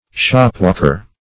Shopwalker \Shop"walk`er\, n.